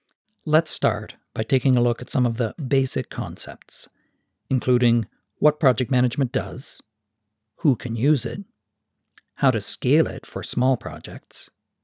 Hi, the attached has been equalized, normalized, de-clicked, and sped up 16 percent.
The excerpt attached sounds a bit mechanical to me.
I just played your original clip way up at the top and it sounds like you’re trying to force-read your voice lower than it wants to go. Instead of a pleasant vocal range you’re missing the bottom few notes.
You have slightly punchy SS sounds.